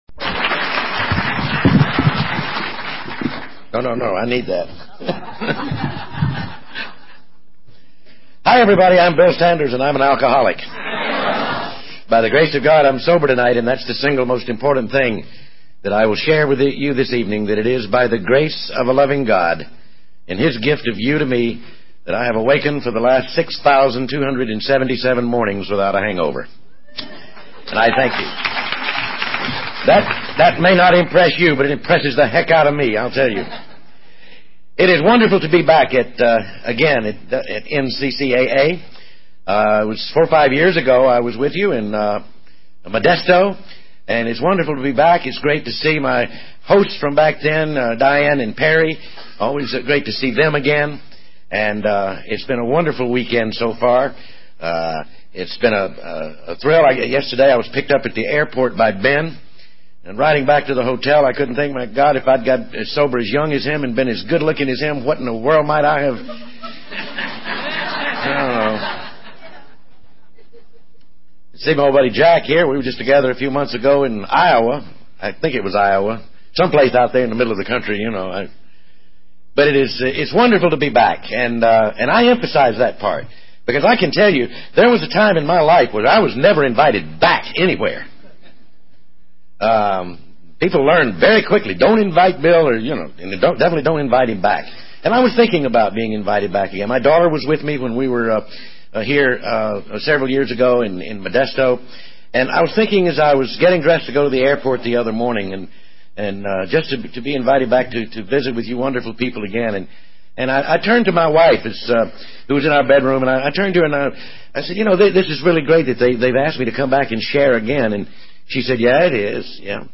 Speaker Tape
AA Convention Tapes